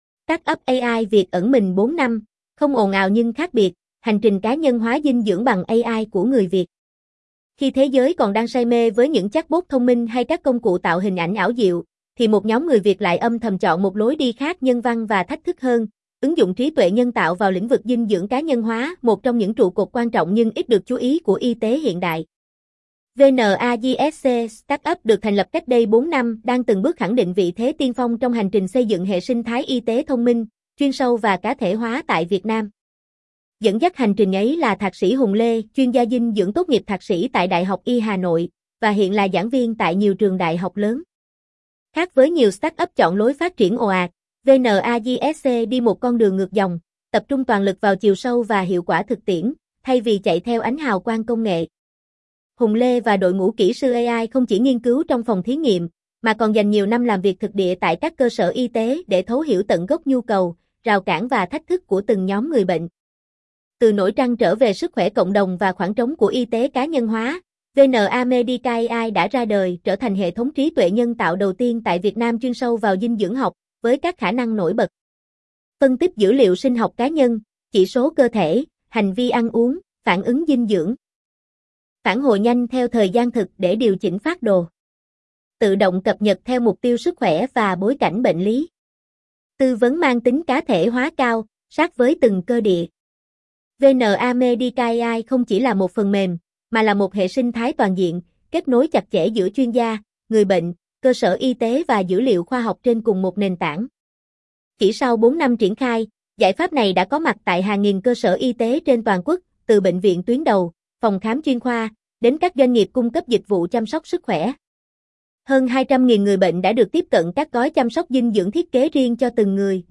giong-nu-tone-nhe.mp3